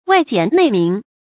外簡內明 注音： ㄨㄞˋ ㄐㄧㄢˇ ㄣㄟˋ ㄇㄧㄥˊ 讀音讀法： 意思解釋： 謂對人表面上簡易，而內心明察。